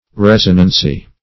resonancy - definition of resonancy - synonyms, pronunciation, spelling from Free Dictionary Search Result for " resonancy" : The Collaborative International Dictionary of English v.0.48: Resonancy \Res"o*nan*cy\ (-nan-s?), n. Resonance.